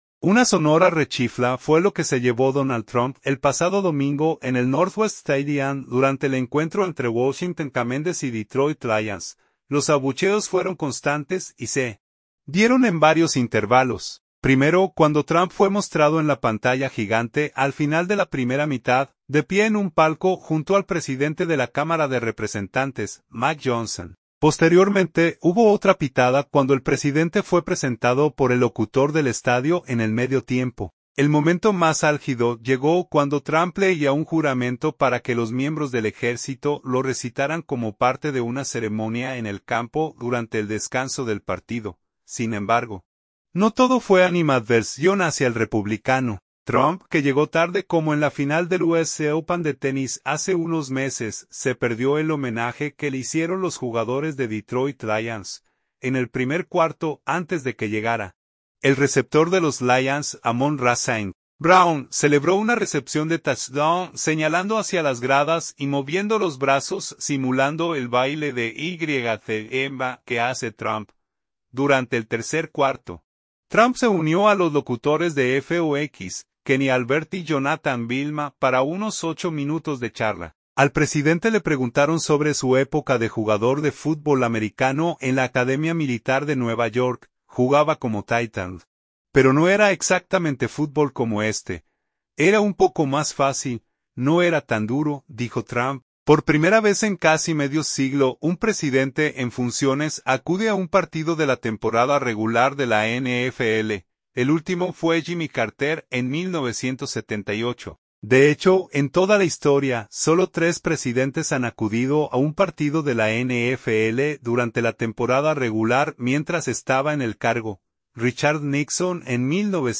Una sonora rechifla fue lo que se llevó Donald Trump el pasado domingo en el Northwest Stadium durante el encuentro entre Washington Commanders y Detroit Lions.
Los abucheos fueron constantes y se dieron en varios intervalos.